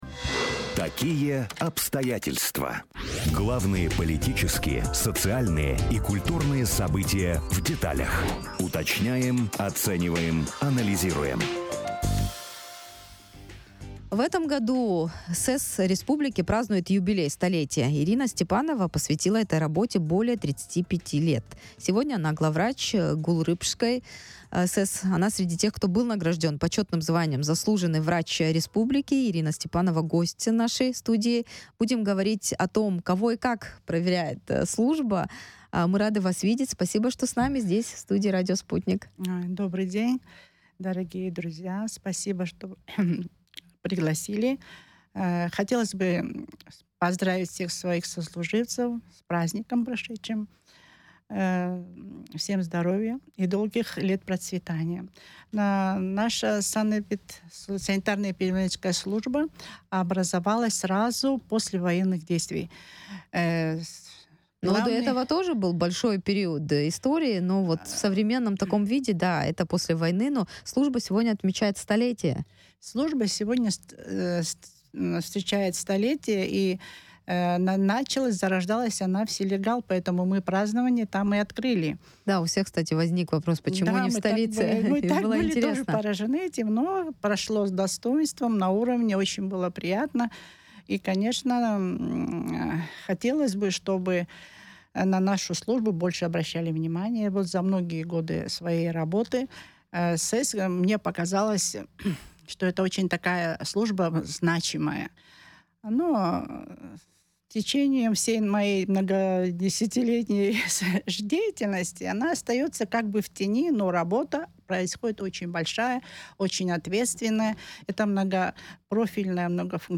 100 лет СЭС Абхазии. Интервью с Заслуженным врачом